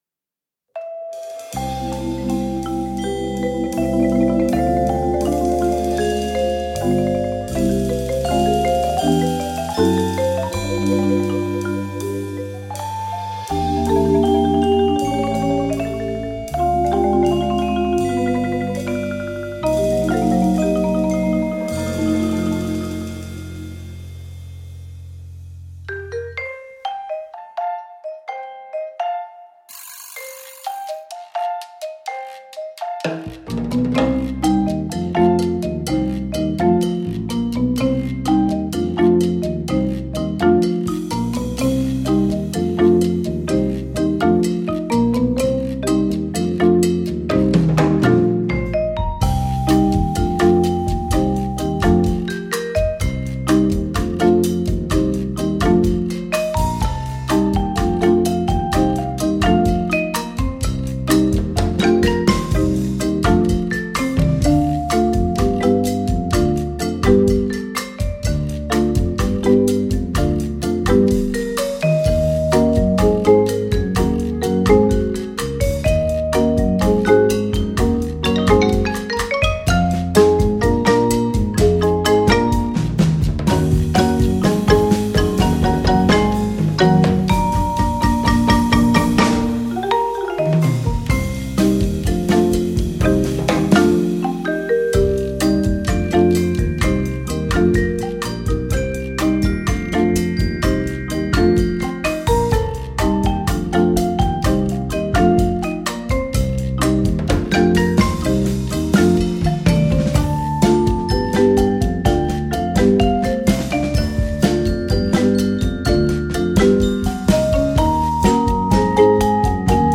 Voicing: Concert